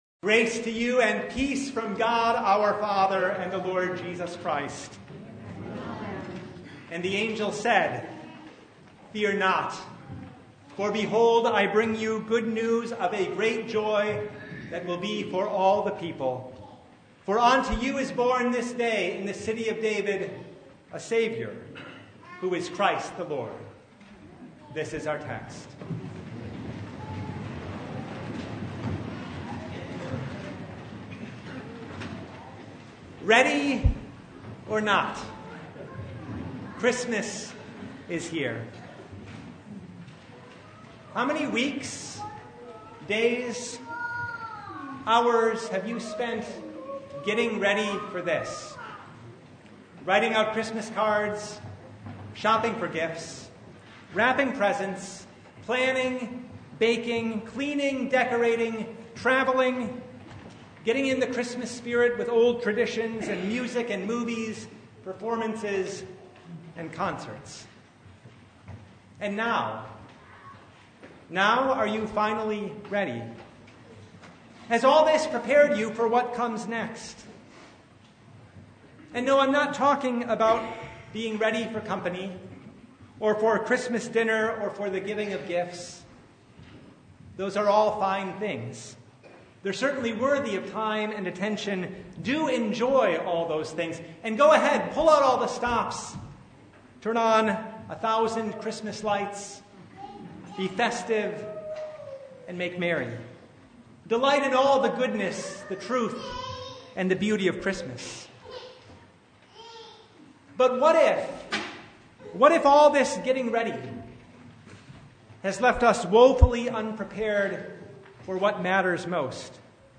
Luke 2:1-20 Service Type: Christmas Eve Bible Text